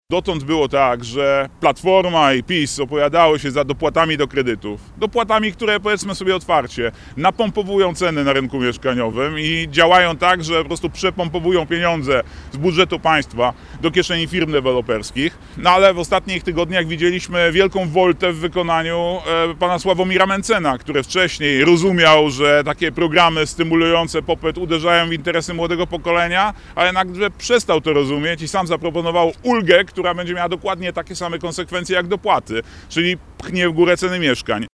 Na zwołanej na Rynku Staromiejskim konferencji przedstawiał swój program.